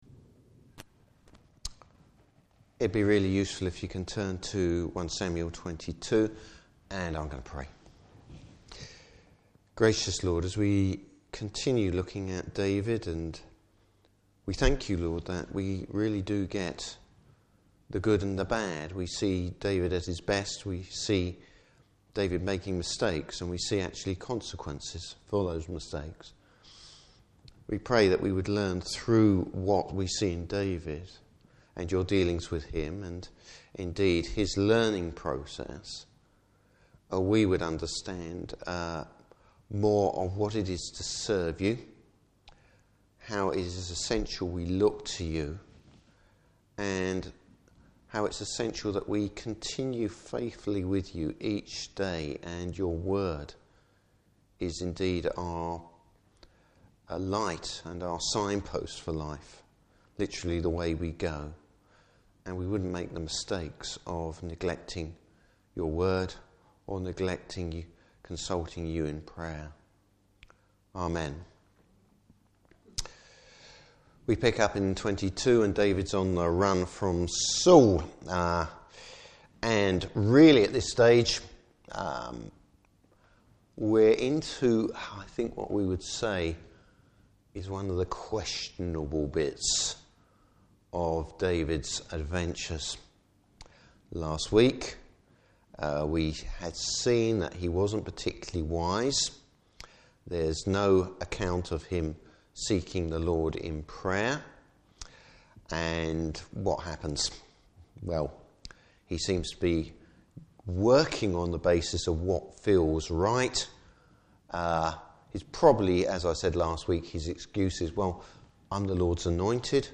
Service Type: Evening Service David learns the consequences of his mistake.